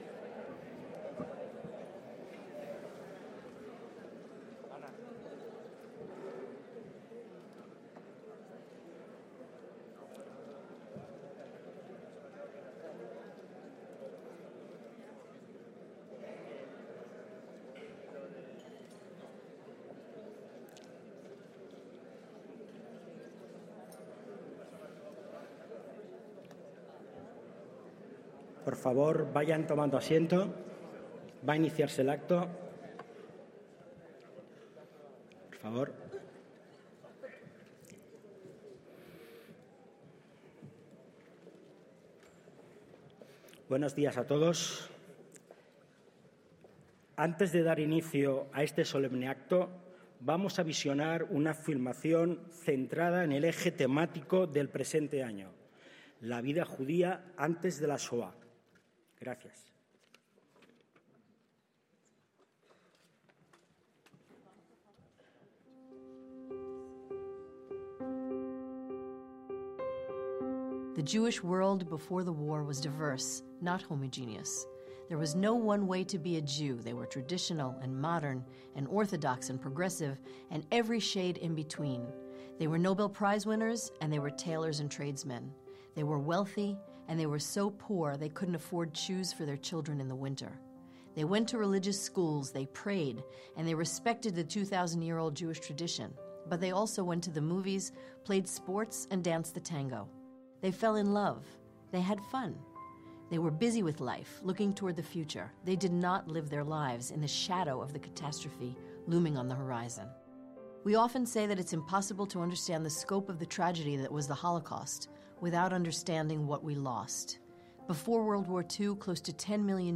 ACTOS EN DIRECTO - El 30 de enero de 2026 tuvo lugar en la Asamblea de Madrid el Acto en Recuerdo del Holocausto, organizado por la Comunidad Judía de Madrid y la Comunidad Autónoma de la capital.